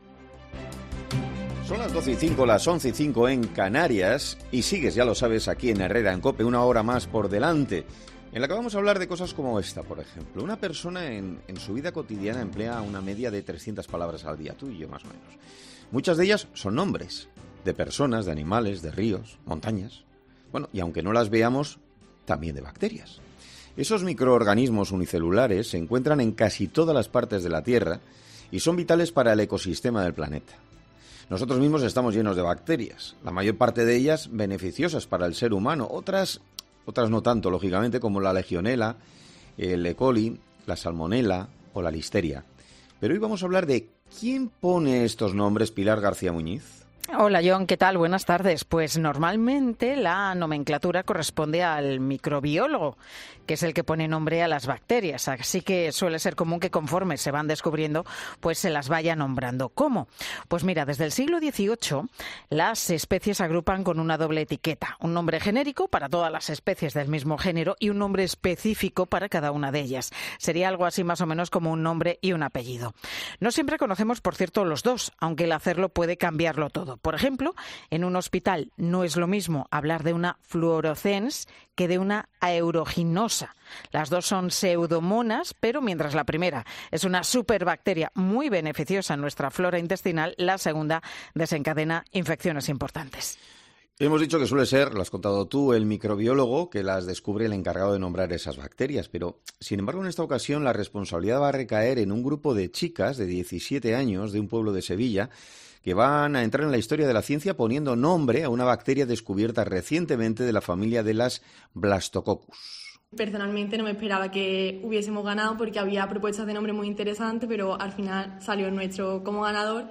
Herrera en COPE' hablamos con